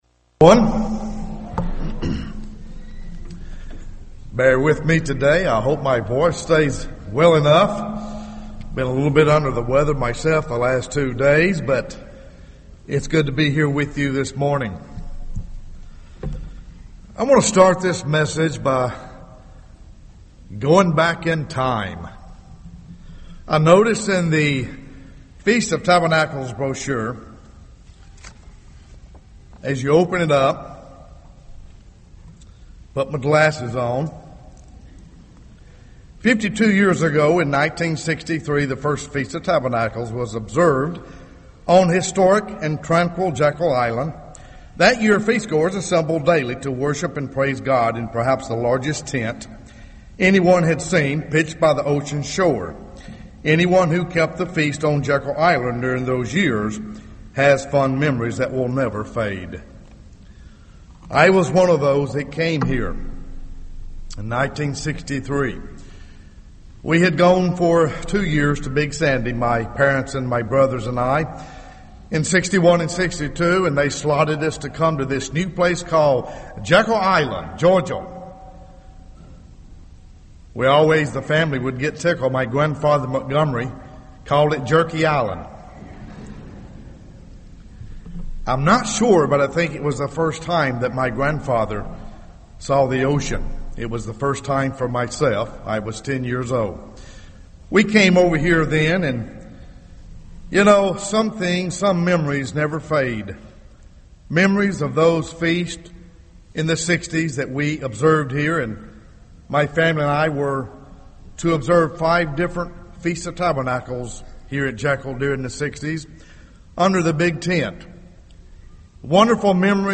This sermon was given at the Jekyll Island, Georgia 2015 Feast site.